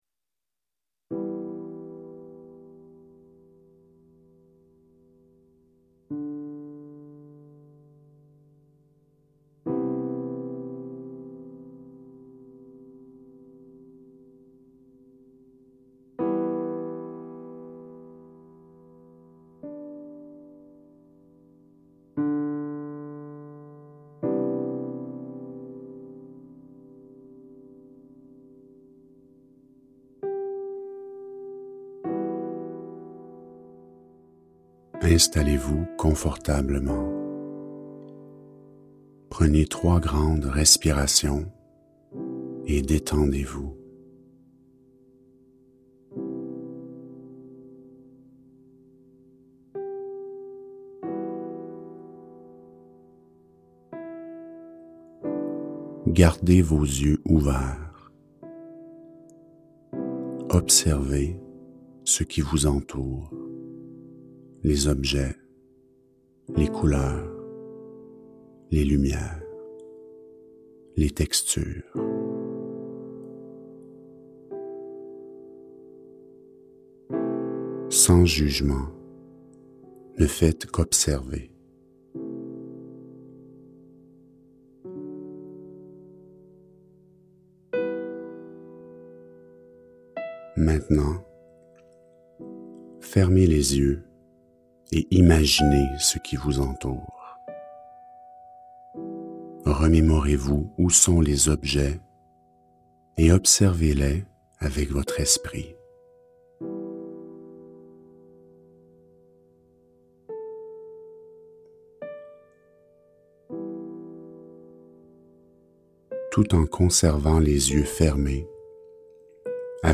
Méditations guidées pour atteindre la sérénité mentale - Volume 2